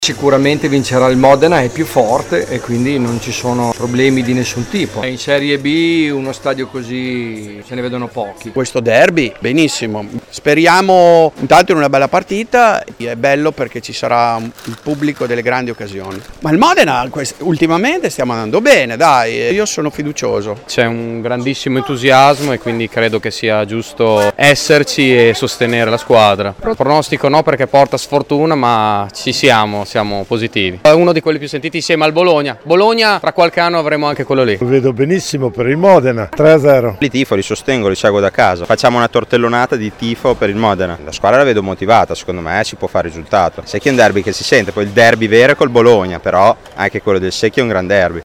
I tifosi gialloblù ci credono, le interviste